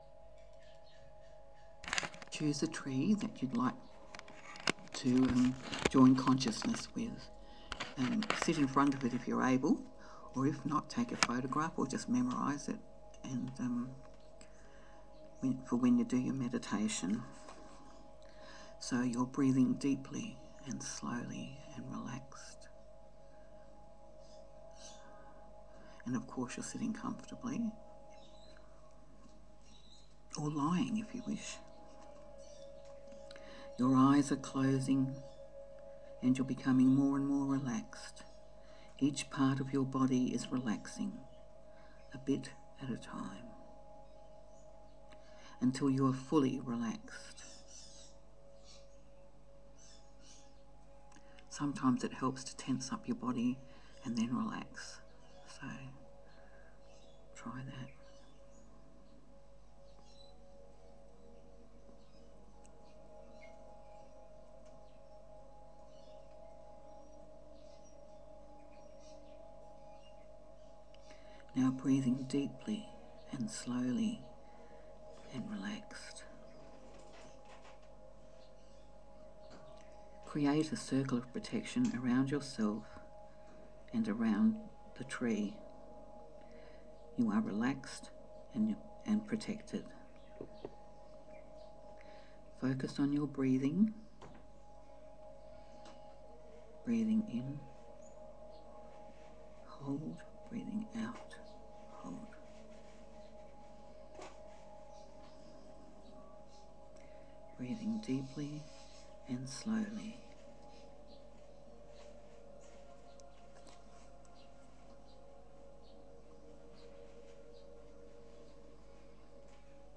For tree lovers there is an audio meditation to link with a tree.
The following is a tree meditation
tree-meditation2.m4a